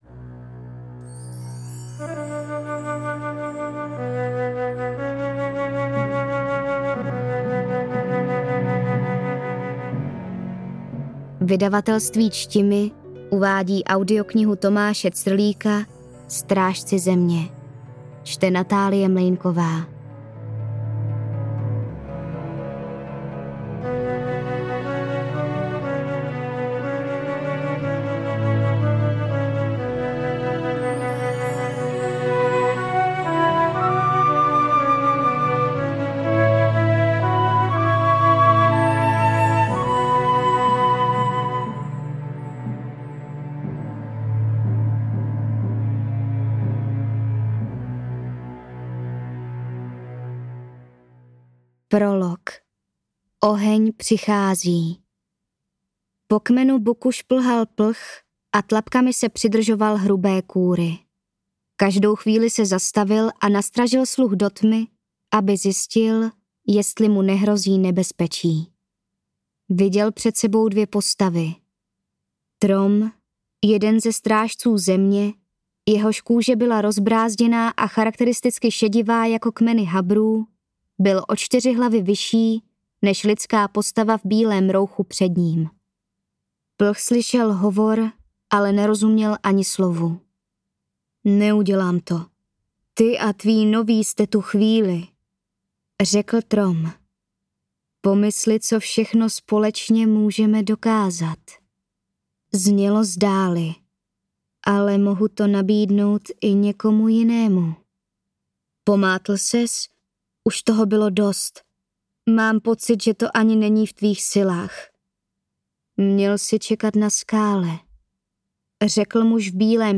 Přednes interpretky je naprosto sterilní, za každou větou je výrazná tečka. Jen v přímé řeči je to trochu živější.
AudioKniha ke stažení, 24 x mp3, délka 8 hod. 42 min., velikost 485,8 MB, česky